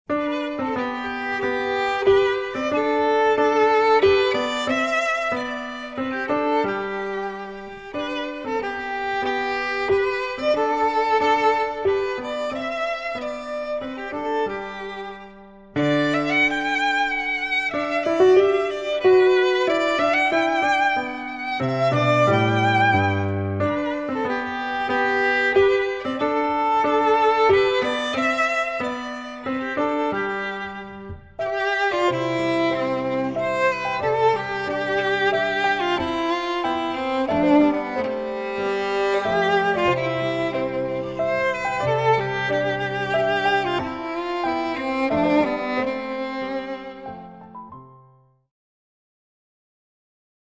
Voicing: Violin and Piano